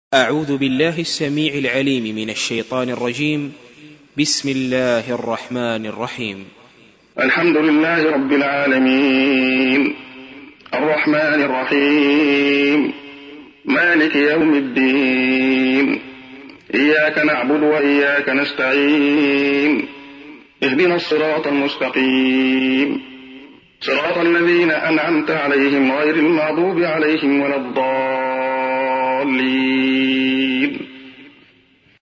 تحميل : 1. سورة الفاتحة / القارئ عبد الله خياط / القرآن الكريم / موقع يا حسين